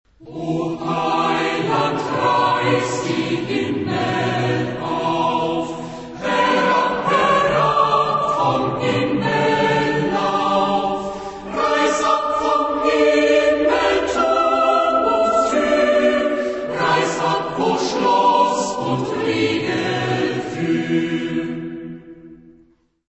Genre-Style-Forme : Sacré
Type de choeur : SATB  (4 voix mixtes )
Tonalité : mi mineur